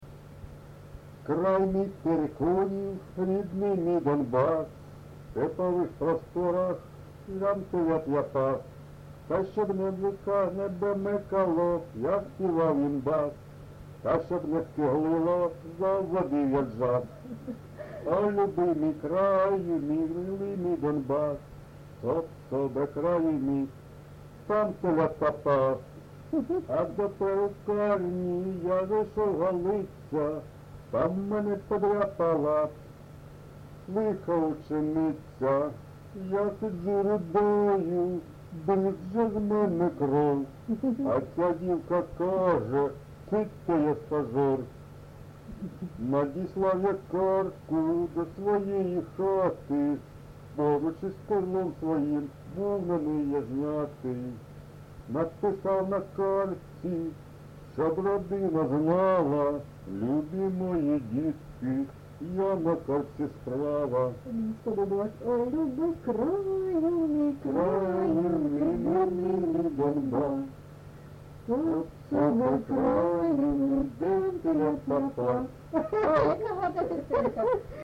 ЖанрЖартівливі, Сучасні пісні та новотвори
Місце записус. Чорнухине, Алчевський район, Луганська обл., Україна, Слобожанщина